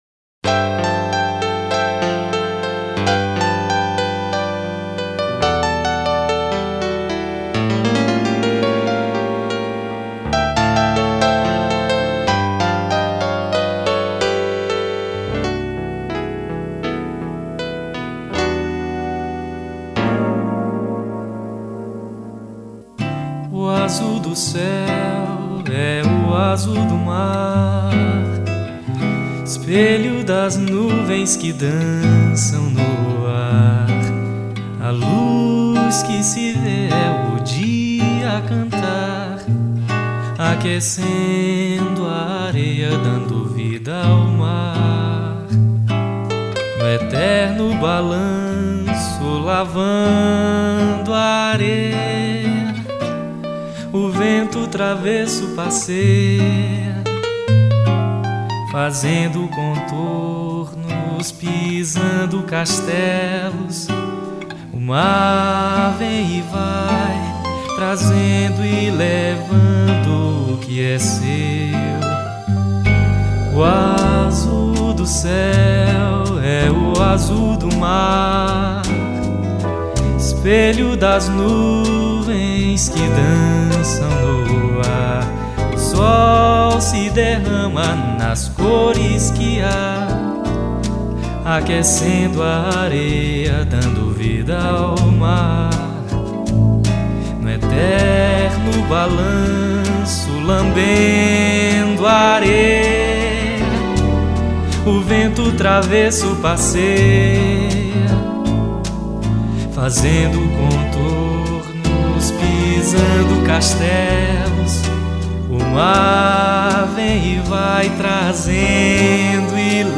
1606   04:39:00   Faixa:     Mpb
Piano Acústico
Bateria
Baixo Elétrico 6